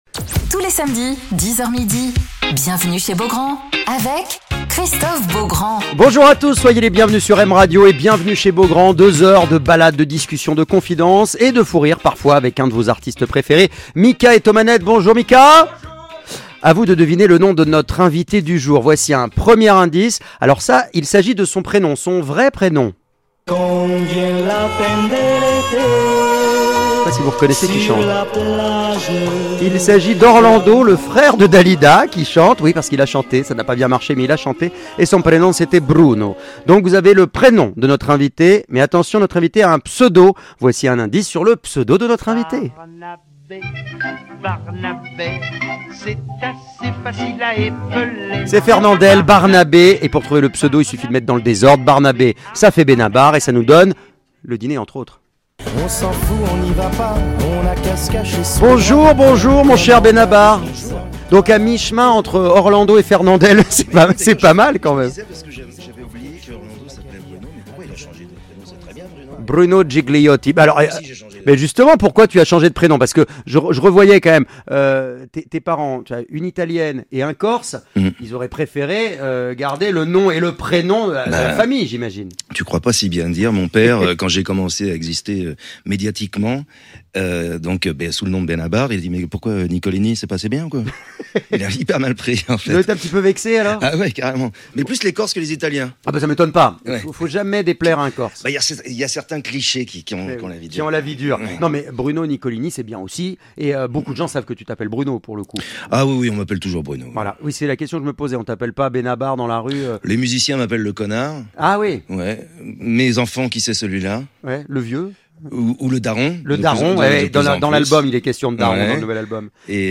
Alors qu'il sort un nouvel album "Le Soleil des Absents", et avant de le retrouver sur scène, notamment à l'Olympia le 29 avril, Bénabar est l'invité de Christophe Beaugrand sur M Radio !